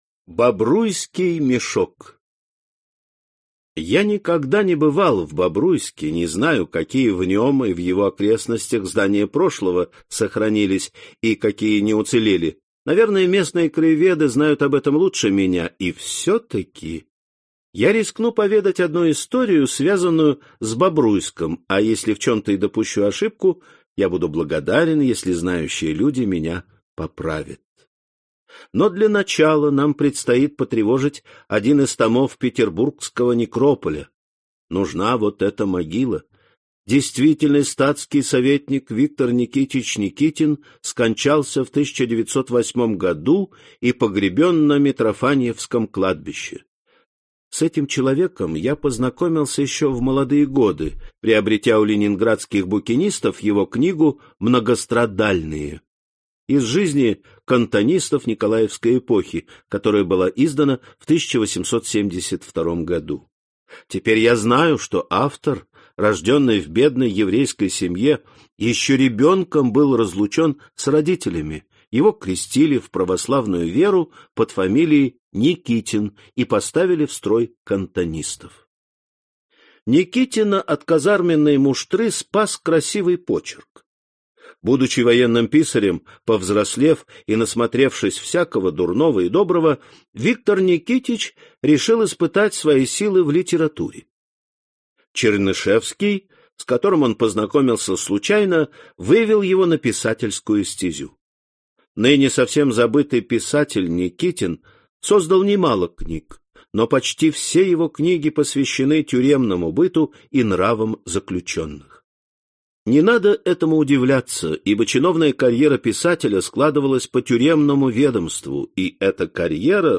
Аудиокнига Железные четки (сборник) | Библиотека аудиокниг
Aудиокнига Железные четки (сборник) Автор Валентин Пикуль Читает аудиокнигу Александр Клюквин.